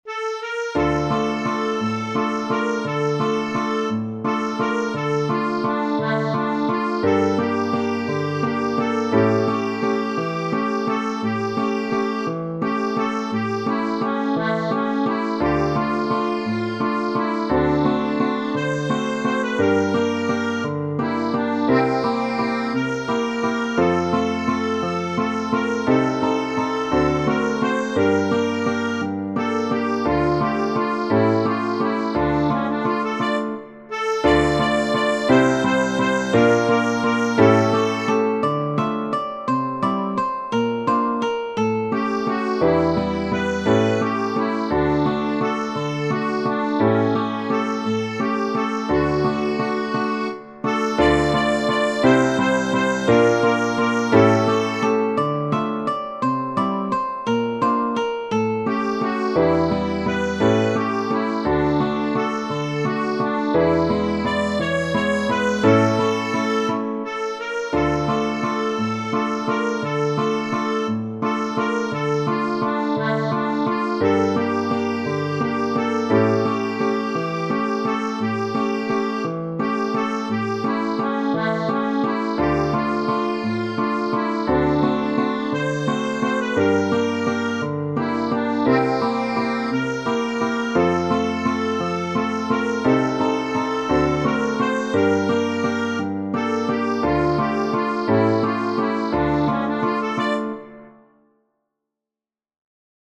Genere: Ballabili